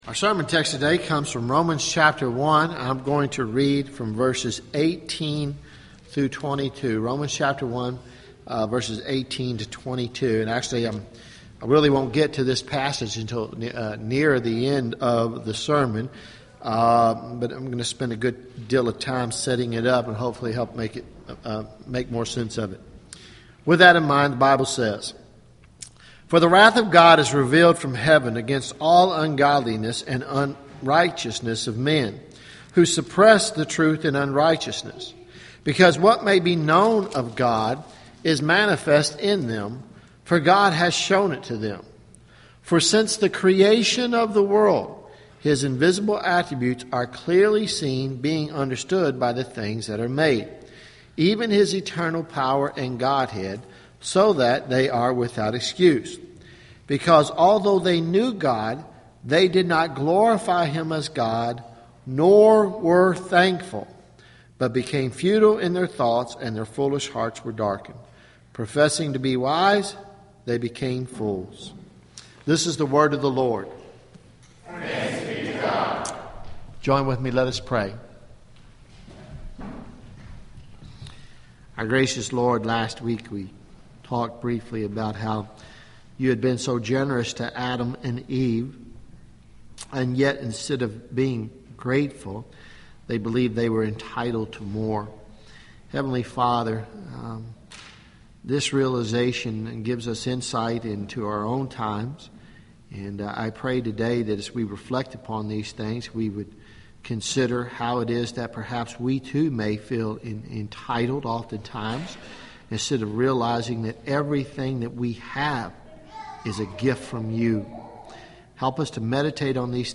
Sermons Nov 16 2014 “Giving Thanks